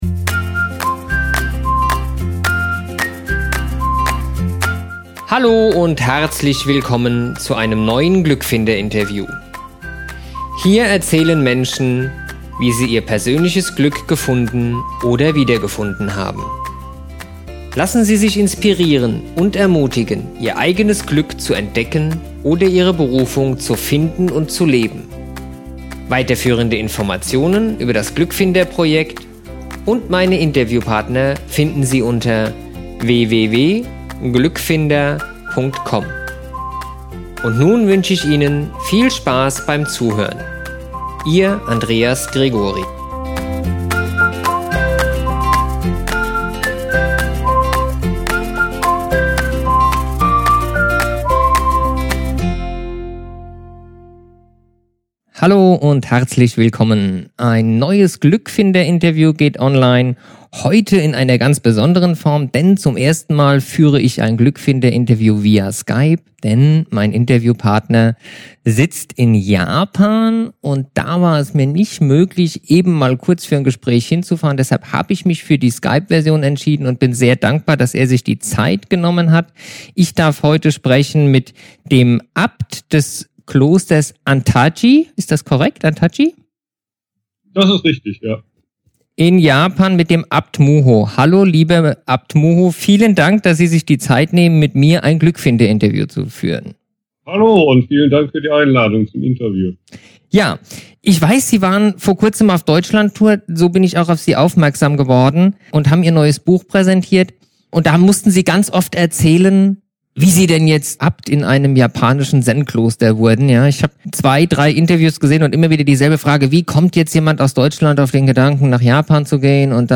Im Interview erklärt er mir, warum es natürlich schon etwas bringt wenn man sich der Stille hingibt. Ich habe in diesem ersten Interview das ich via skype geführt habe sehr viel über Meditation und deren wirkungsweise gelernt und darüber, warum man durch Stille glücklich werden kann.